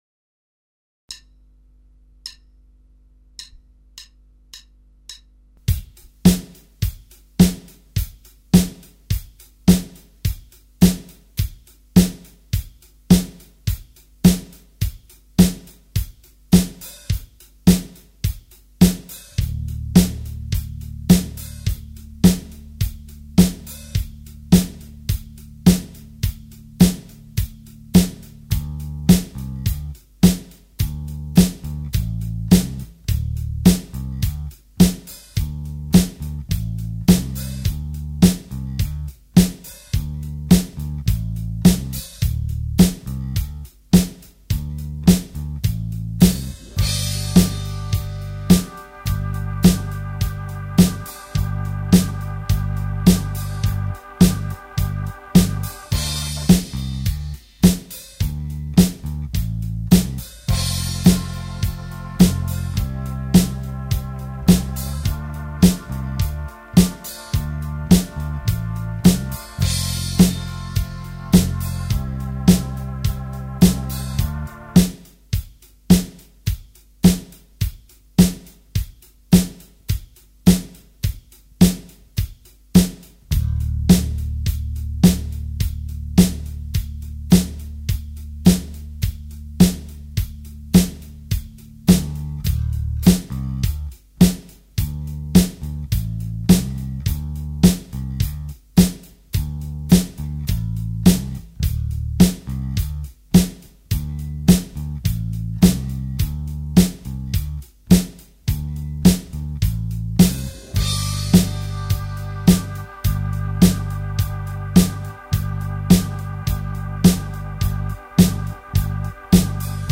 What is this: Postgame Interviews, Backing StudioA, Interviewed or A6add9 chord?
Backing StudioA